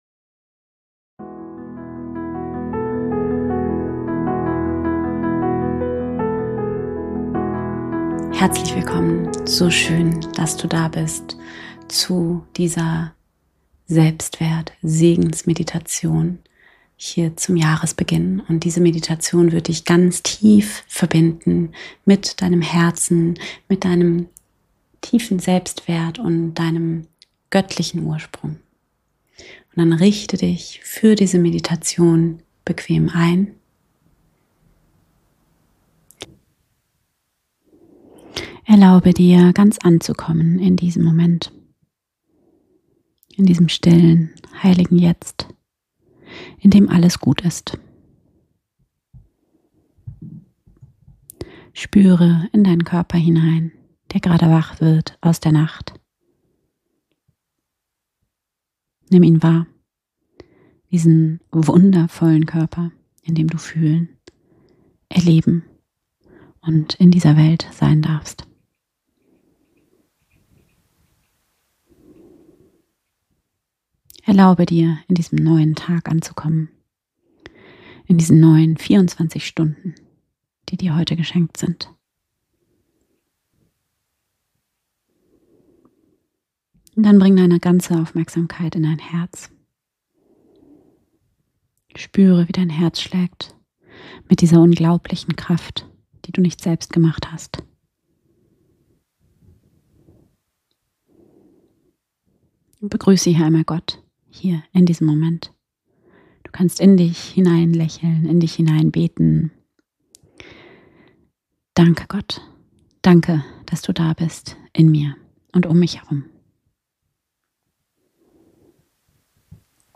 Eine geführte christliche Meditation für mehr Selbstvertrauen und